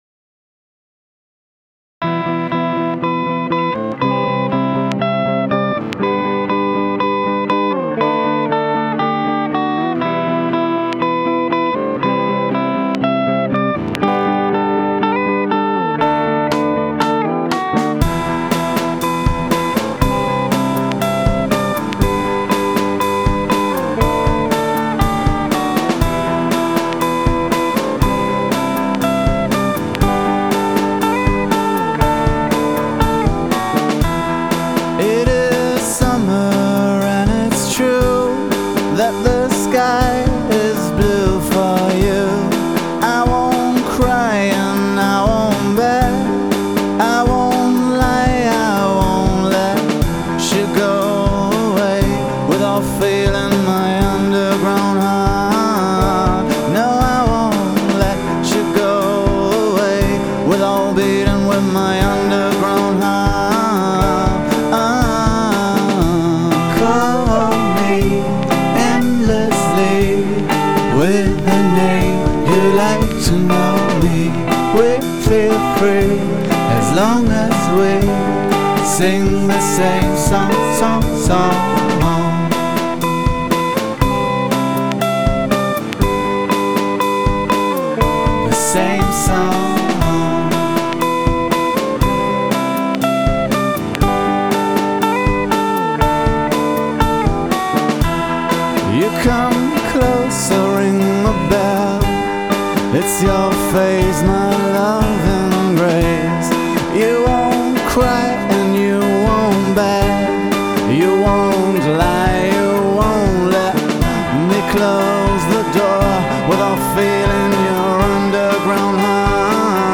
• Genre: Pop